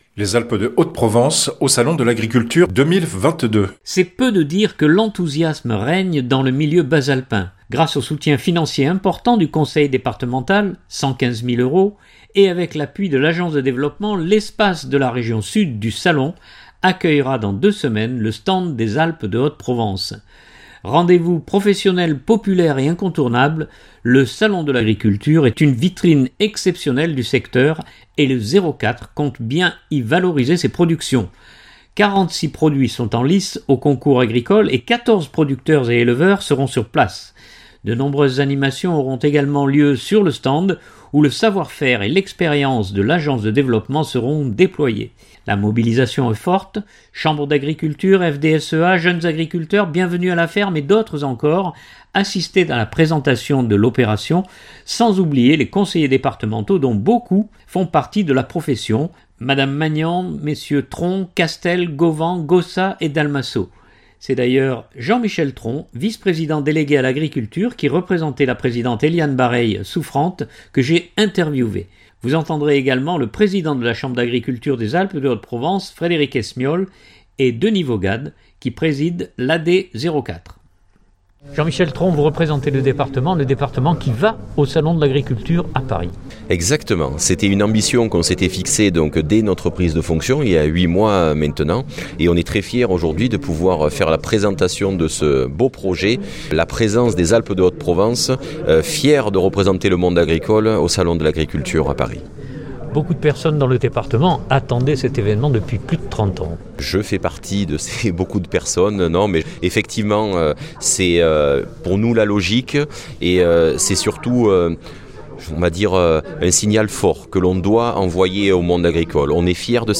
C’est d’ailleurs Jean-Michel Tron, vice-président délégué à l’agriculture qui représentait la présidente Eliane Barreille souffrante que j’ai interviewé.